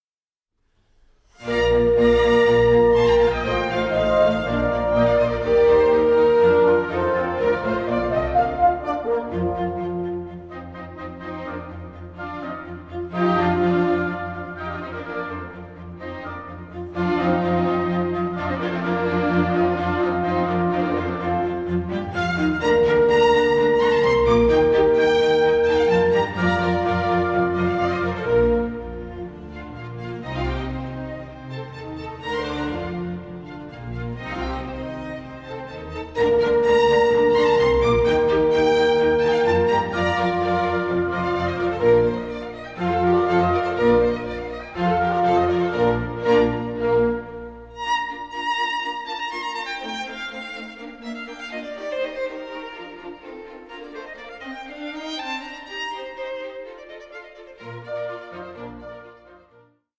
Concerto for Violin & Orchestra No. 1 in B-Flat Major